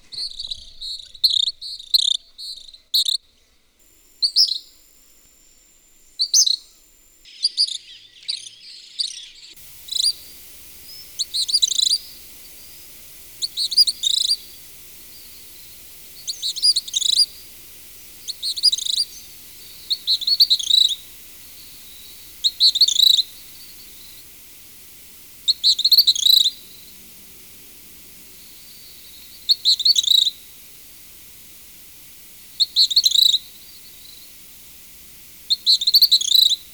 "Gray Kingbird"
Tyrannus dominicensis
pitirre.wav